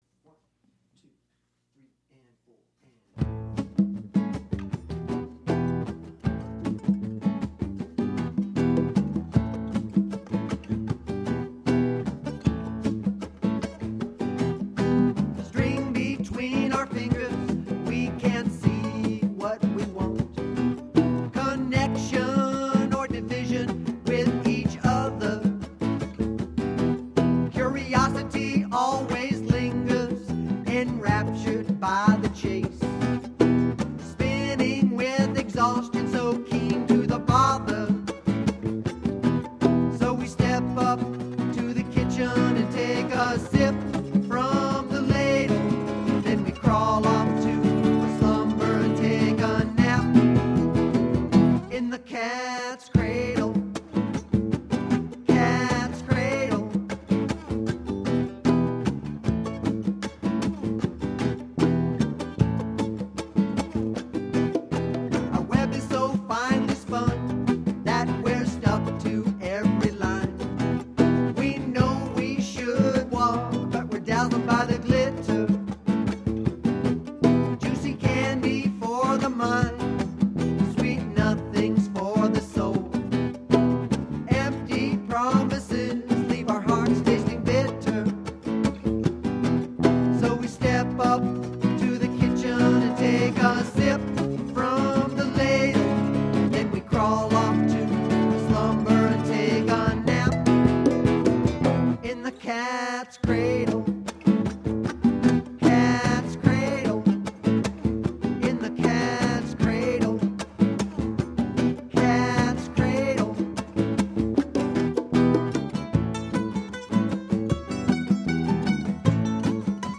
BLUEGRASS FOLK JAM ROCK